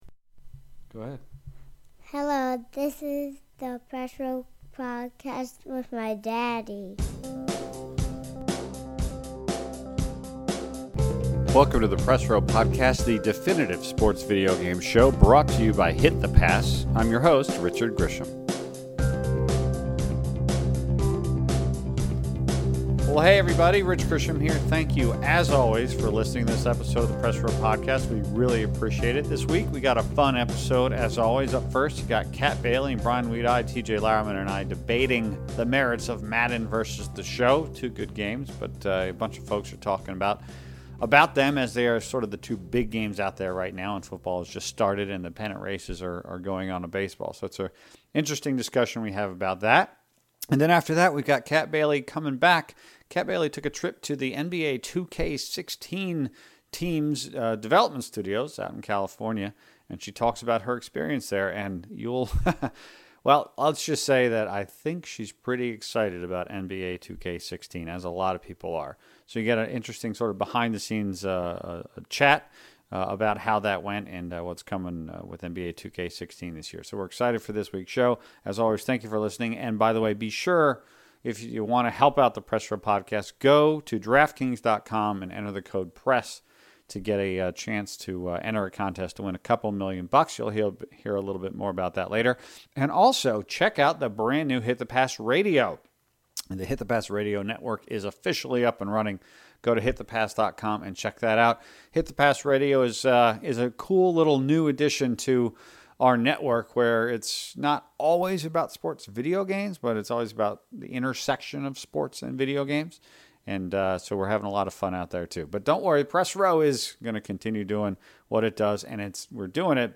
With Madden NFL 16 and MLB 15 The Show as the currently-available big-time sports games (not for long, though, as hockey, soccer, and hoops games are coming out quickly!) a few of our regular Press Row panelists get together to debate the merits of the two against each other.